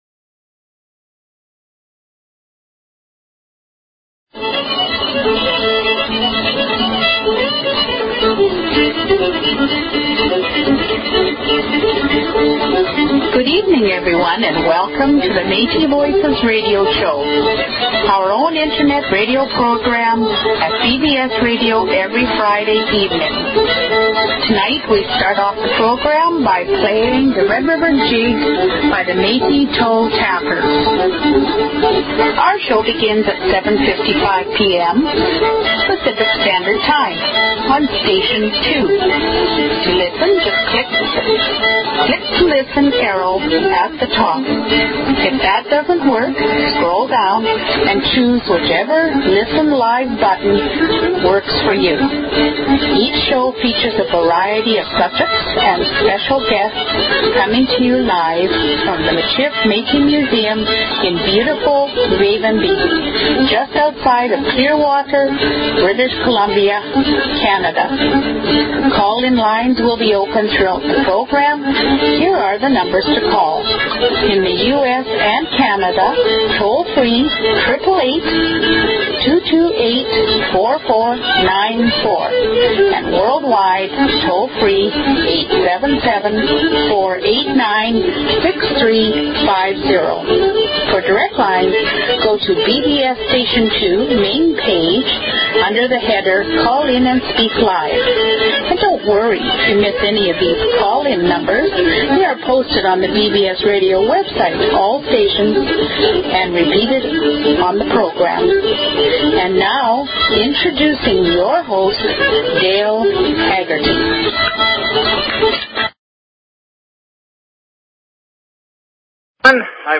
Talk Show Episode, Audio Podcast, Metis_Voices_Radio and Courtesy of BBS Radio on , show guests , about , categorized as
We will have an Open Mike and hour of music to celebrate the Holiday Season We wish our listeners a very Merry Christmas and a Happy Holiday Season to all....and look forward to a healthy and prosperous 2009 for everyone...